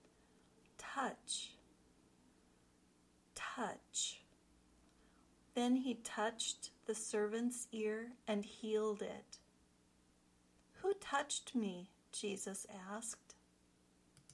Then, listen to how it is used in the sample sentences.
tʌtʃ (verb)